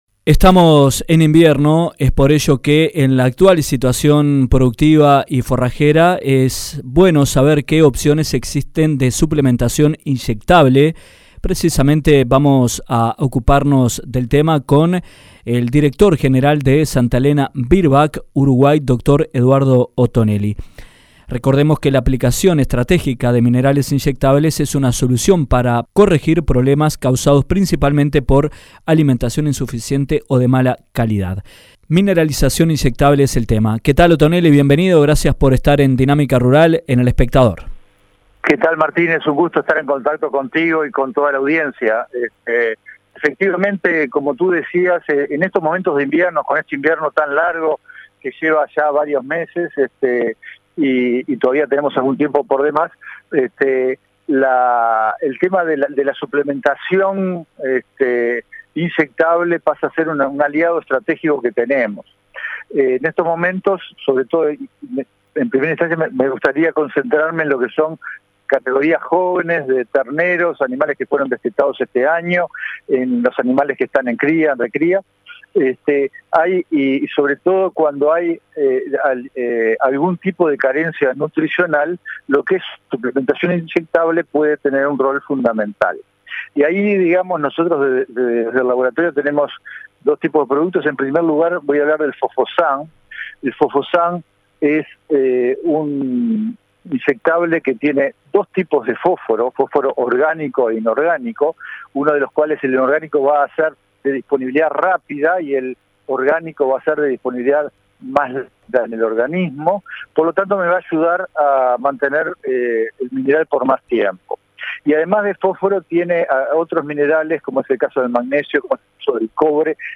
En entrevista con Dinámica Rural